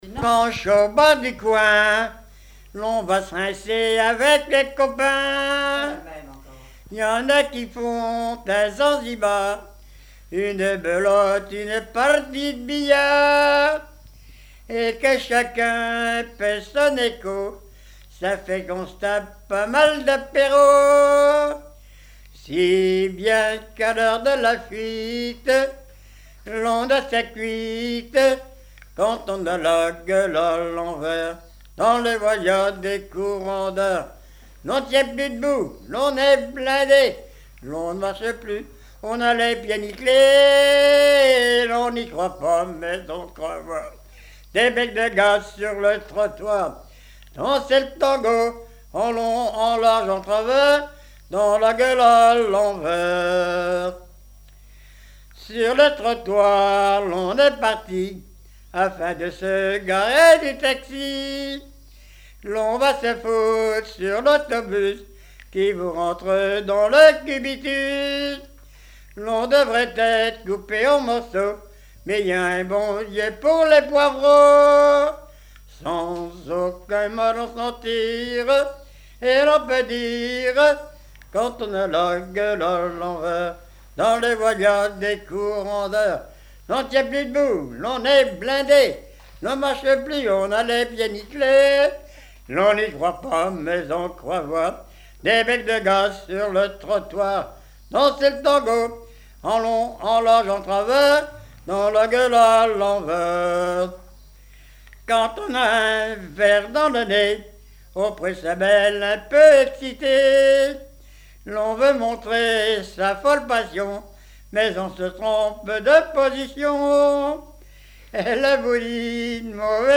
circonstance : bachique
Genre strophique
recueil de chansons populaires
Pièce musicale inédite